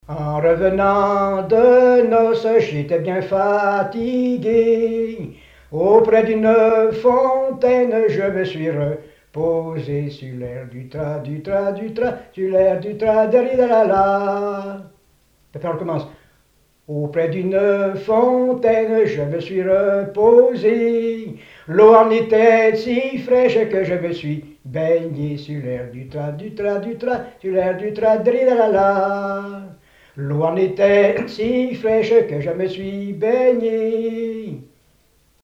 Genre laisse
chansons et témoignages parlés
Pièce musicale inédite